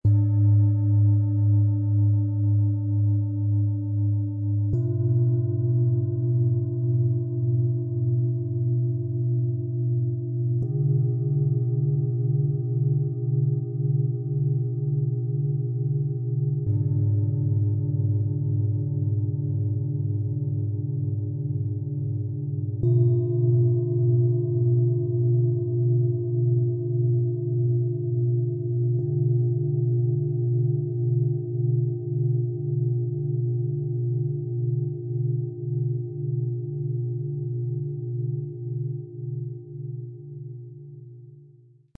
Die Schalen lassen sich einzeln oder im harmonischen Dreiklang verwenden.
Im Sound-Player - Jetzt reinhören lässt sich der Original-Ton genau dieser Schalen anhören - die harmonische Klangkomposition dieses einzigartigen Sets wird hörbar.
Tiefster Ton: Mond, Saturn, Merkur
Bengalen-Schale, glänzend
Mittlerer Ton: Lilith, Chiron
Höchster Ton: Mars, Uranus, Tageston
MaterialBronze